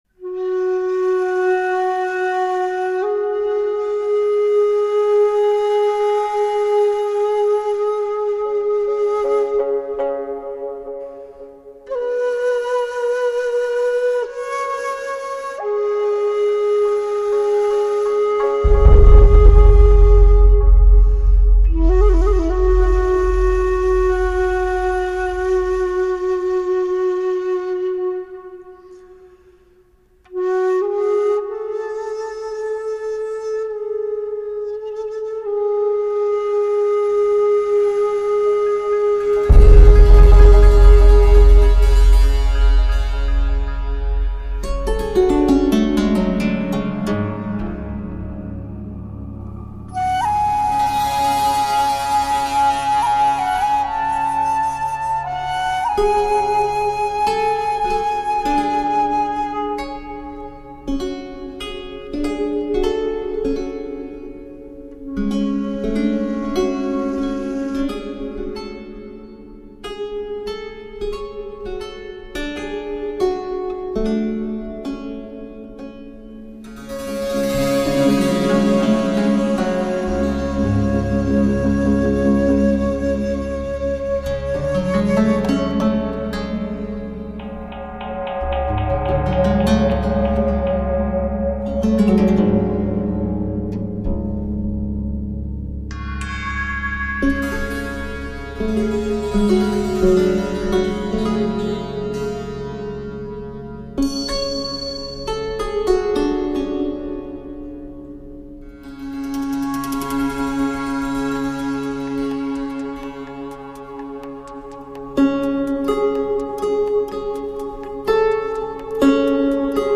我们把这灵魂的语言唱出来，献给十方世界诸佛菩萨，唱给十方世界一切众生。
这清净微妙之音将把您引向一个崇高圣洁的境界。